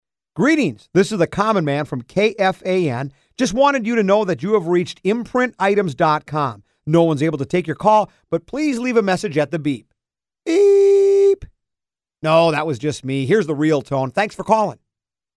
Voicemail